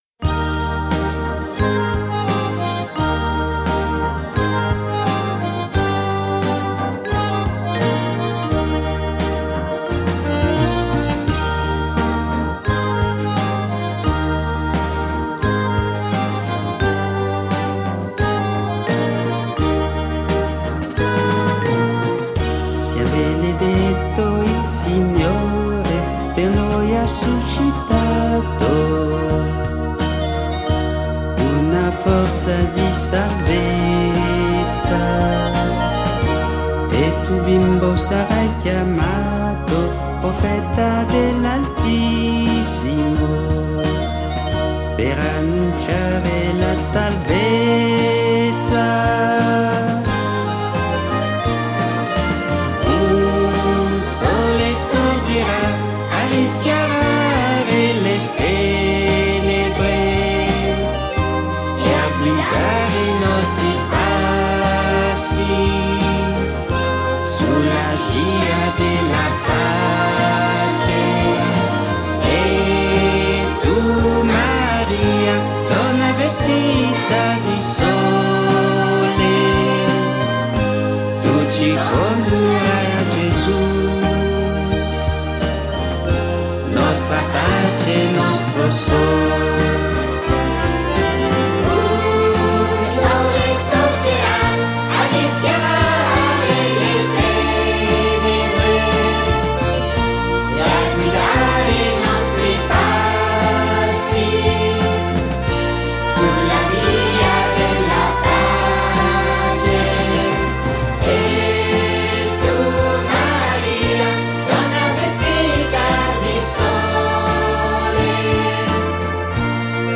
Canto per la Decina di Rosario e Parola di Dio: Sia benedetto il Signore